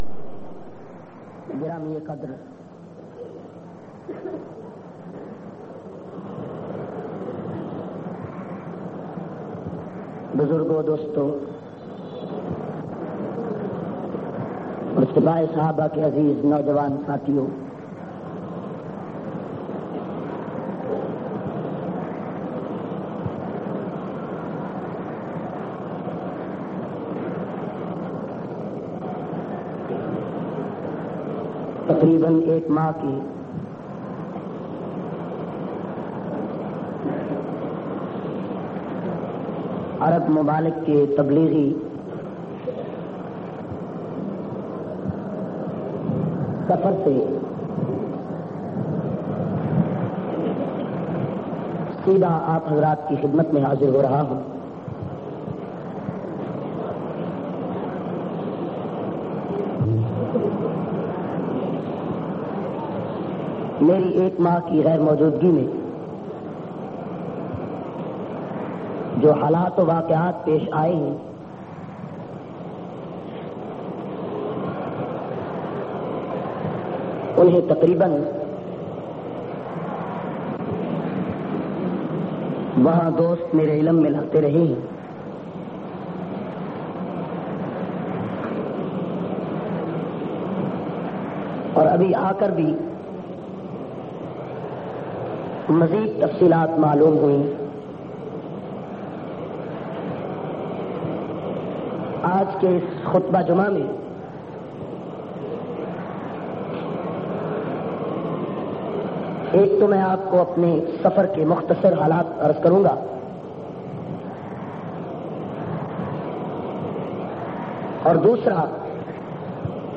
37- Arab Mamalik Tableeghi Daory se Wapsi py Bayan Jhang.mp3